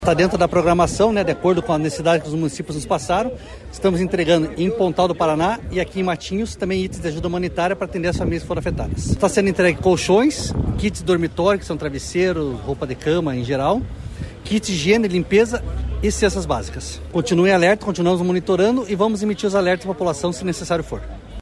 Sonora do coordenador Estadual de Defesa Civil, coronel Fernando Schunig, sobre os mantimentos entregues pela Defesa Civil no Litoral